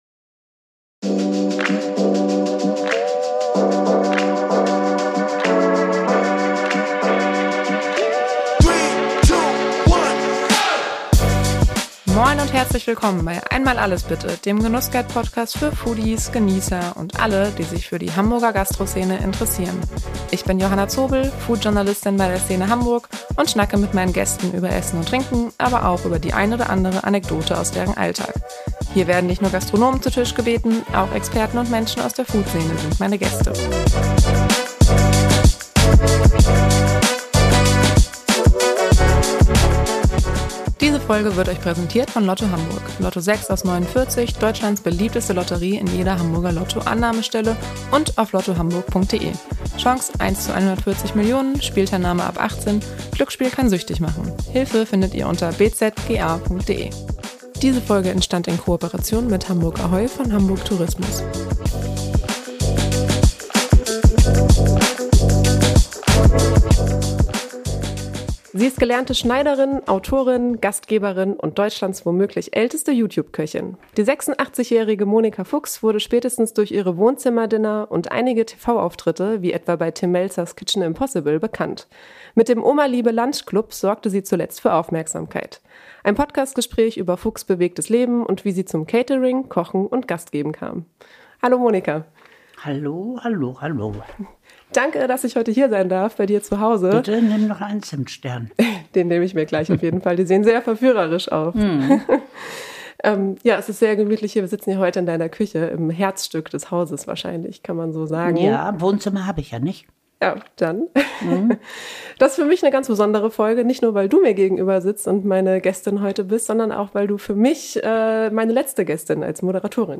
Im Podcast-Gespärch bei „Einmal alles, bitte!“